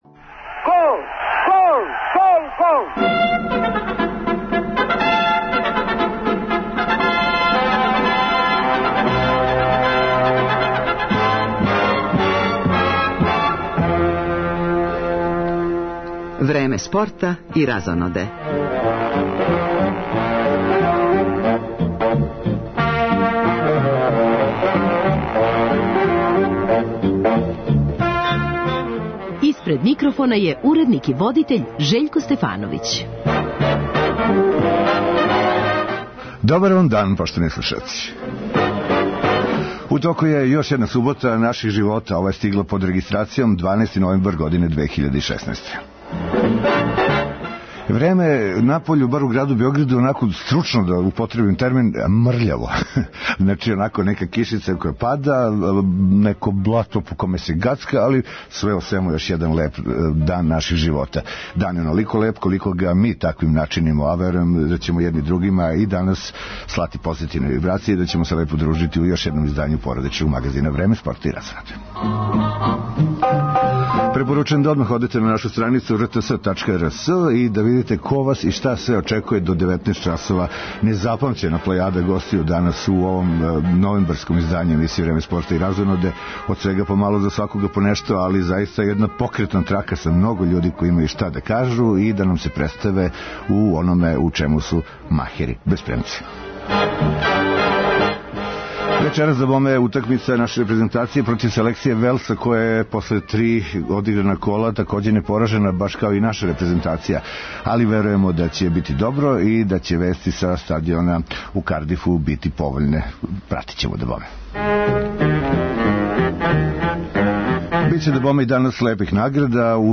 И ове суботе емисија Време спорта и разоноде обилује информацијама из света спорта, као и јавног, културног и уметничког живота. Доста гостију је такође у студију